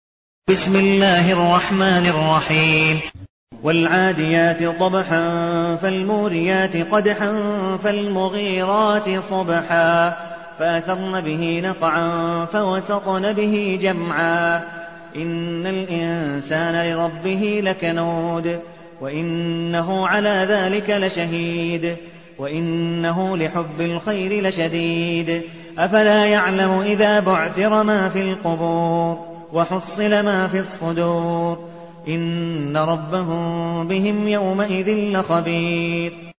المكان: المسجد النبوي الشيخ: عبدالودود بن مقبول حنيف عبدالودود بن مقبول حنيف العاديات